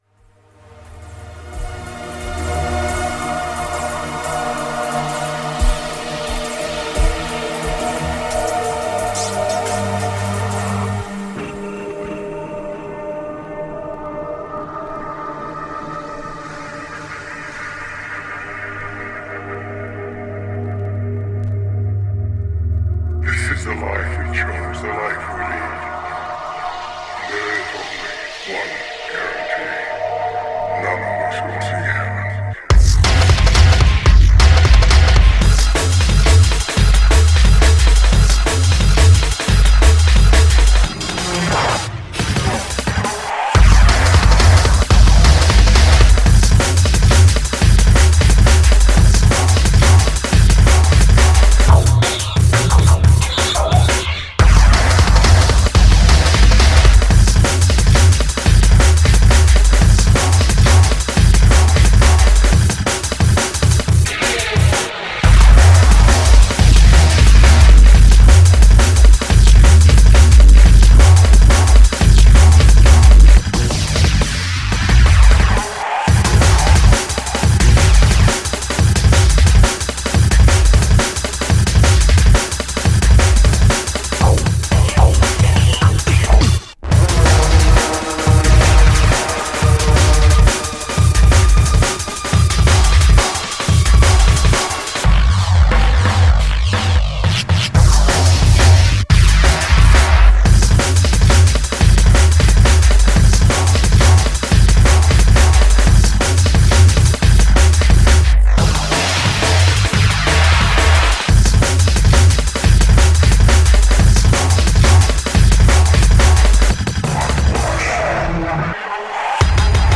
Styl: Drum'n'bass, Hardtek/Hardcore